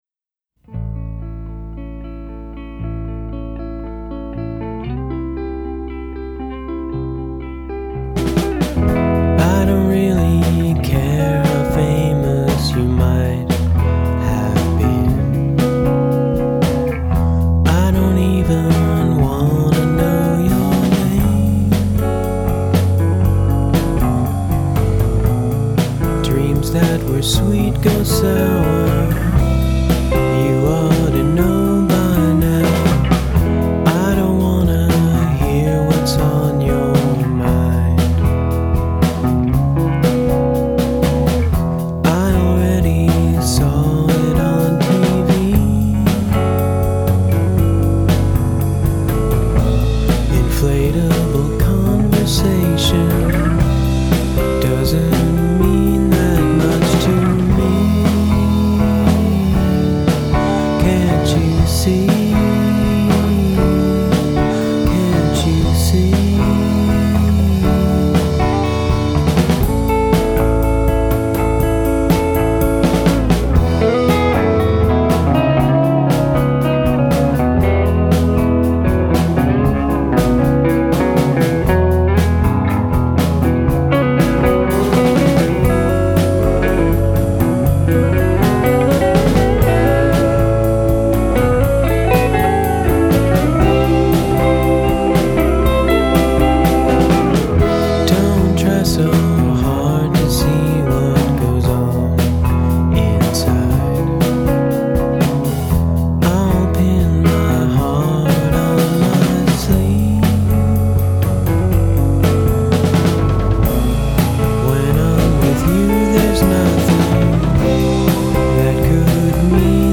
slow countrified neo-psychedelic cosmic gaze
bassist